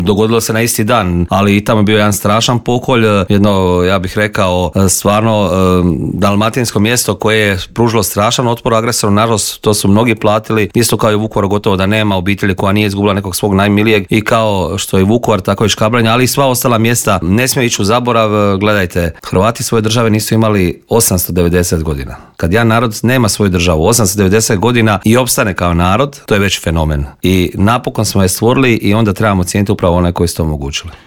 O ovogodišnjoj obljetnici i brojnim drugim političkim aktualnostima u Intervjuu Media servisa razgovarali smo s predsjednikom Hrvatskih suverenista Marijanom Pavličekom koji je poručio: "Čovjek se naježi kada vidi sve te mlade ljude koji idu prema Vukovaru."